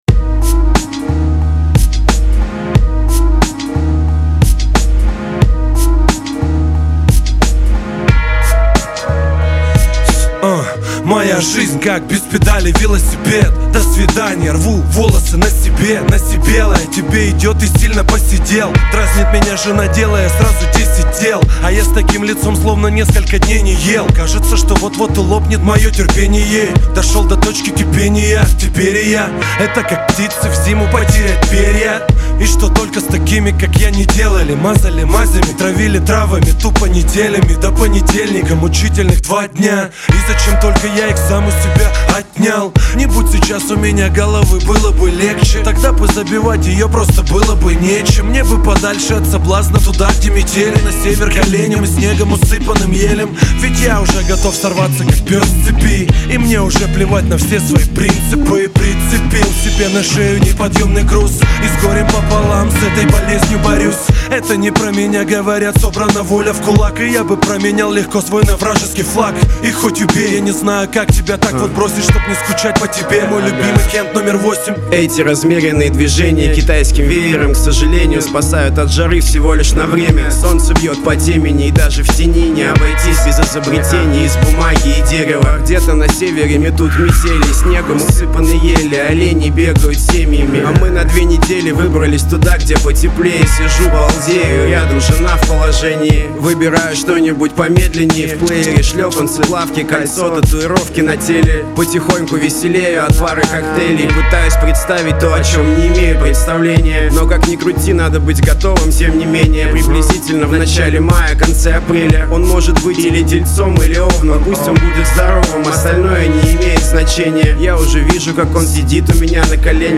Категория: Русский реп, хип-хоп